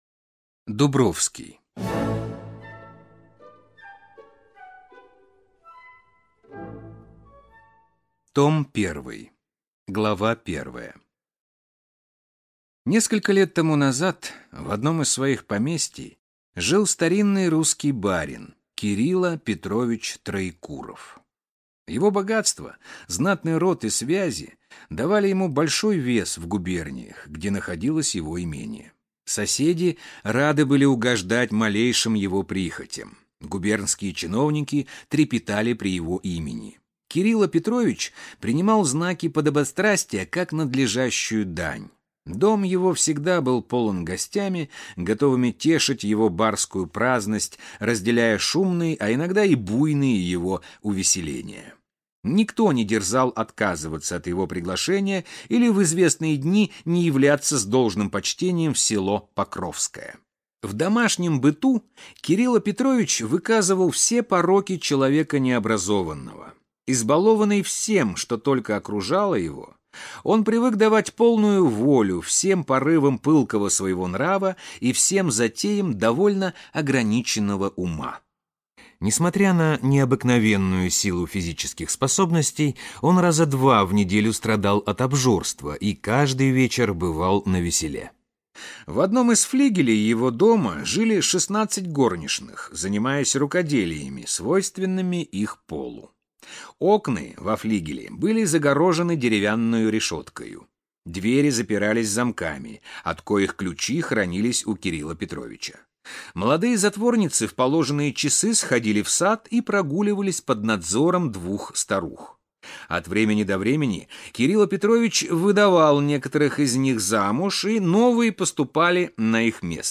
Аудиокнига Дубровский | Библиотека аудиокниг